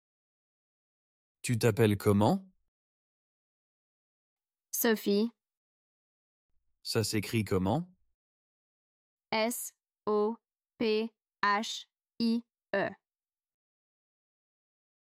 Dialogue – Se présenter et épeler un nom (Niveau A1)
Dans ce dialogue, deux personnes parlent pour se présenter. Une personne demande le prénom de l’autre et comment il s’écrit.